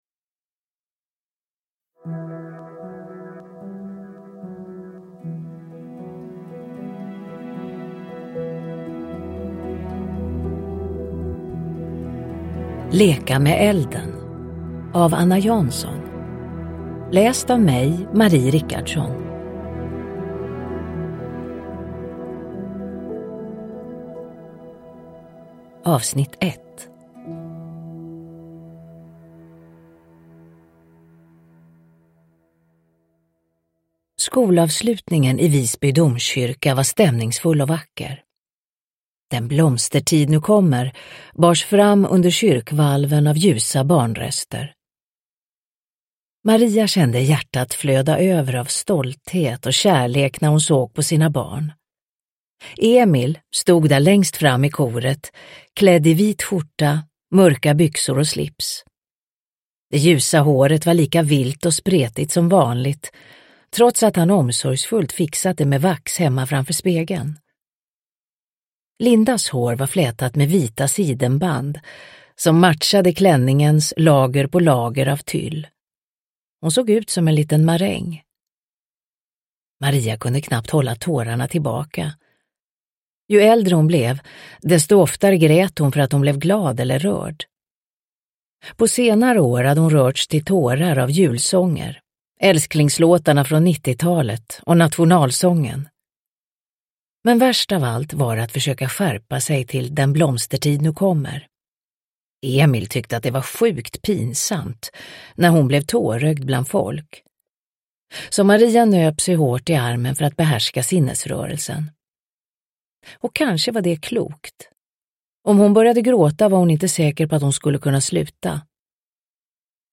Leka med elden - 1 – Ljudbok – Laddas ner
Uppläsare: Marie Richardson